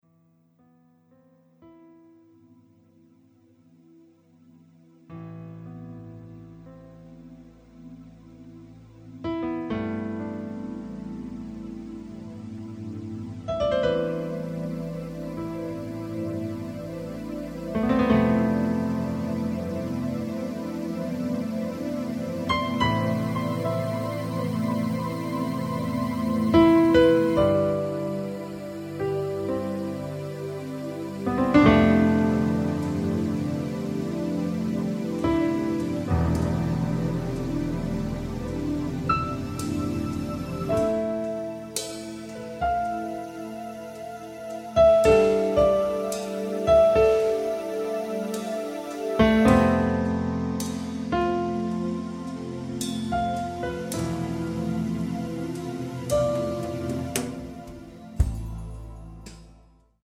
Experimental instrumental music